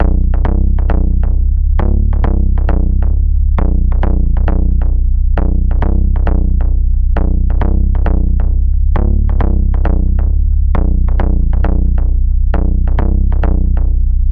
• HardGroove - Techno Pitch Bend Bass (8) - B - 134.wav
HardGroove_-_Techno_Pitch_Bend_Bass_(8)_-_B_-_134_cCX.wav